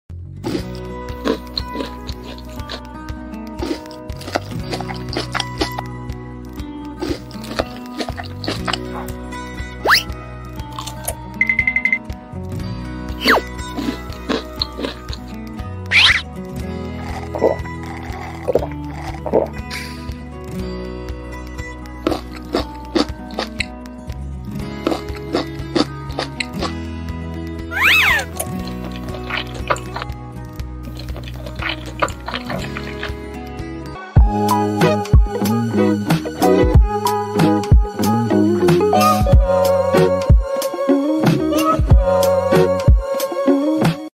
Bubbles eating cotton candy, ice